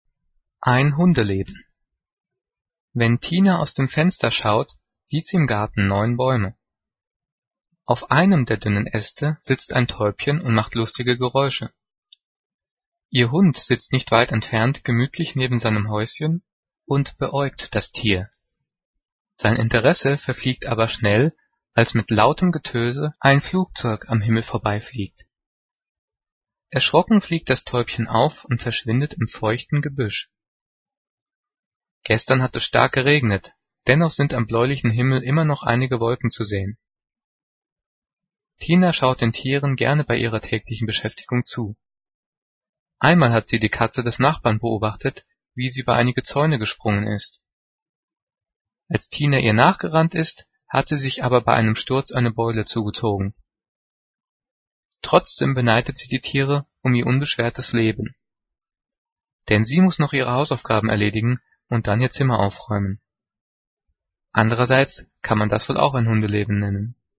Gelesen:
gelesen-ein-hundeleben.mp3